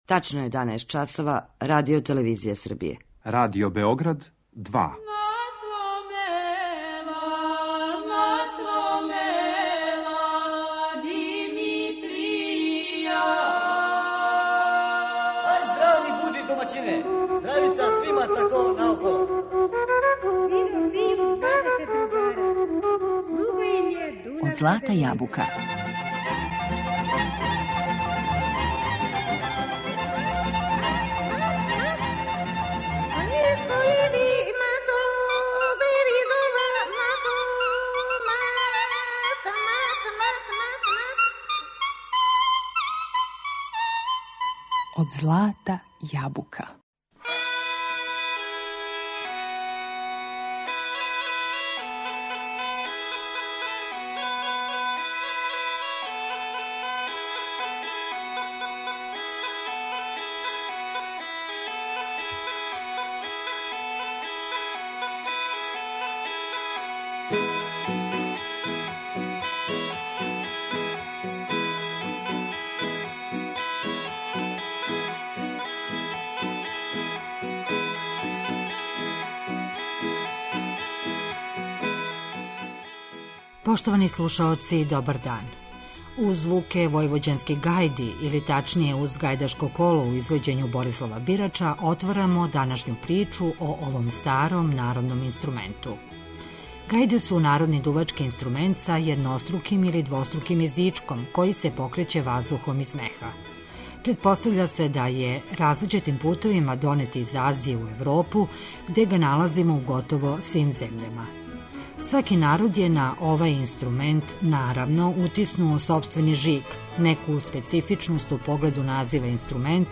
Војвођанске гајдаше представићемо и кроз причу и кроз песму. Теренско снимање реализовано је у јулу 2015. у Сивцу.